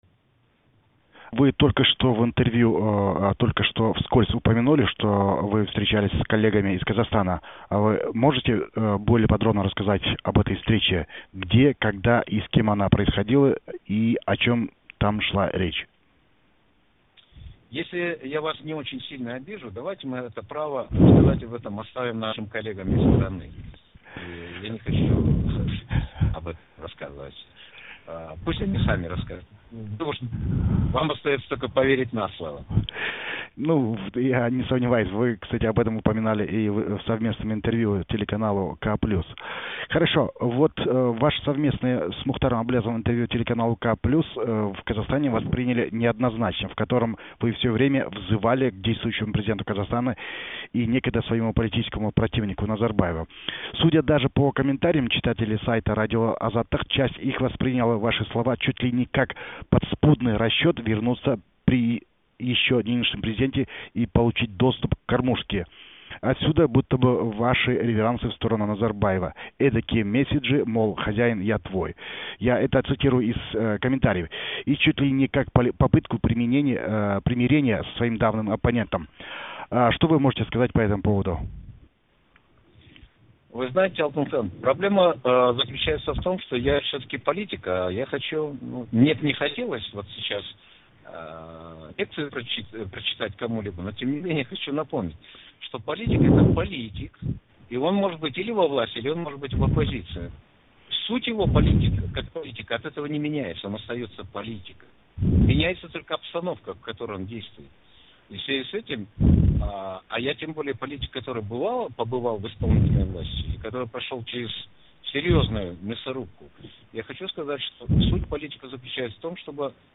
Интервью с Акежаном Кажегельдиным. Часть-2